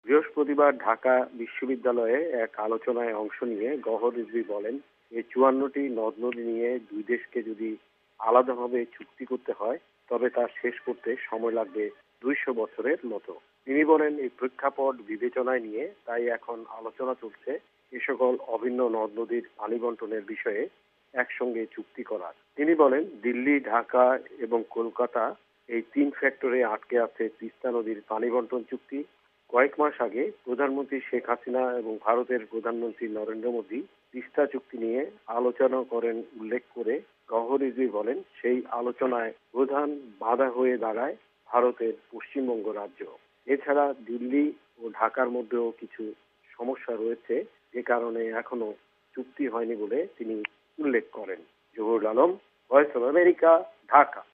রিপোর্ট (তিস্তা)